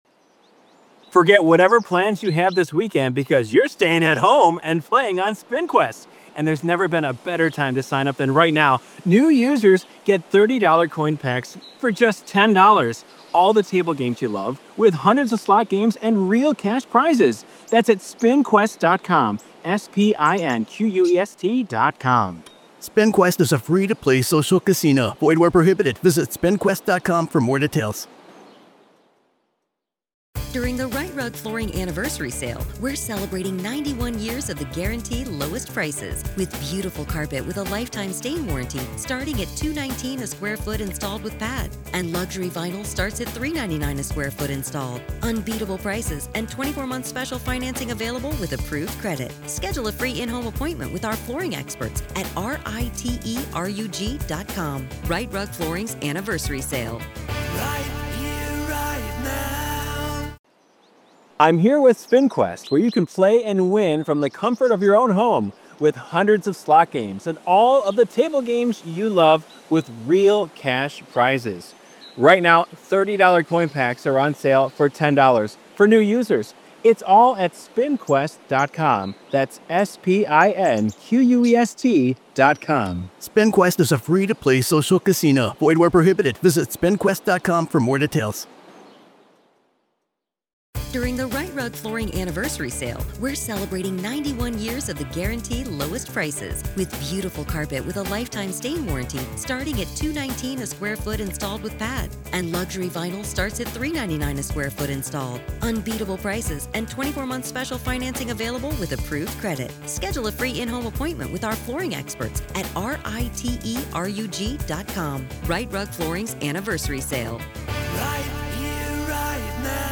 RAW COURT AUDIO-AZ v. Lori Daybell, Pretrial Hearing- Part 1